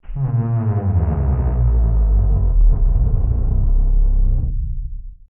MOAN EL 08.wav